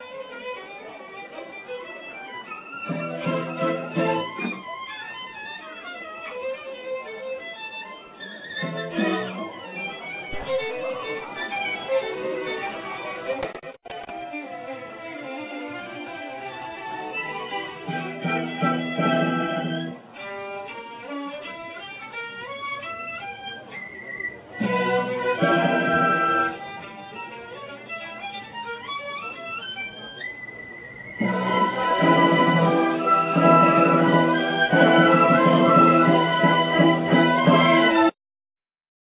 στο κοντσέρτο για βιολί και ορχήστρα
του Mendelsssohn στις 1.8.2001 στο Ελληνικόν Ειδύλλιον